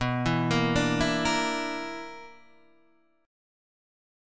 B Minor Major 7th Double Flat 5th